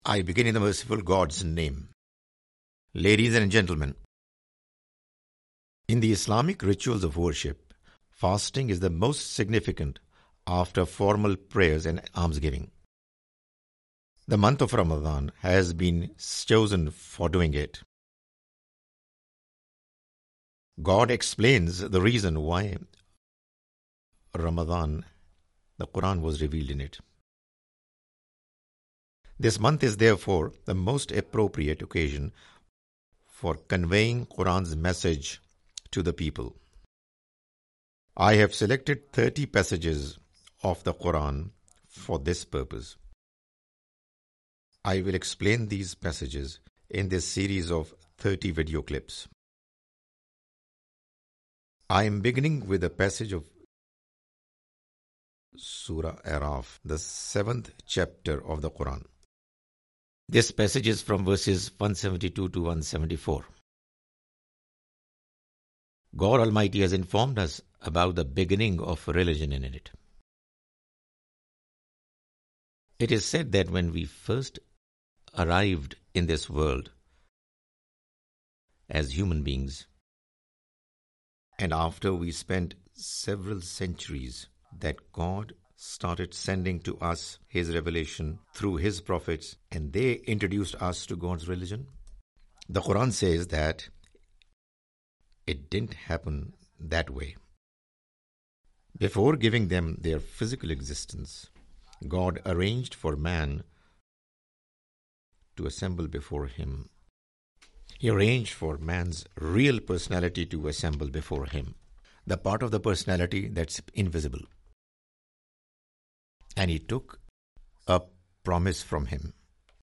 The Message of Qur'an (With English Voice Over) Part-1
The Message of the Quran is a lecture series comprising Urdu lectures of Mr Javed Ahmad Ghamidi.